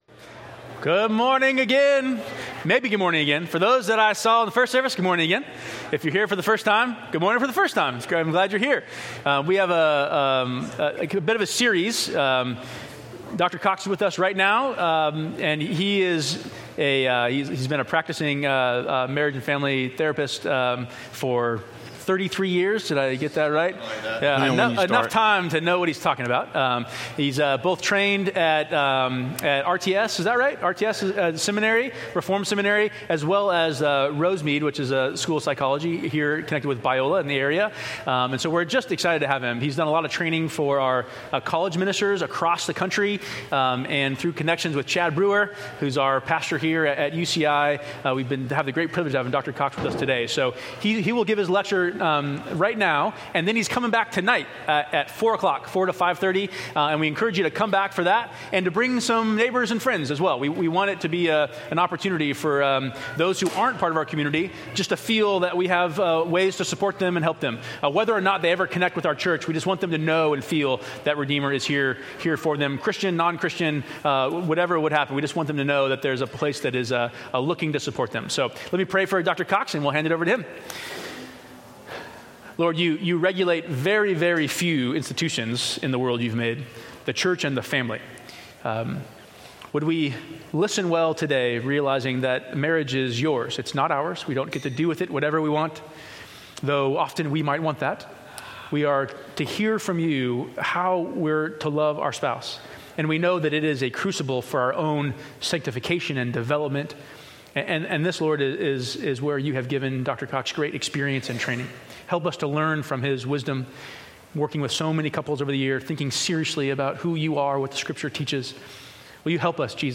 About the Lectures
If you were not able to attend the lectures or if you would like to listen again, see the links below to an audio of the morning lecture and afternoon seminar.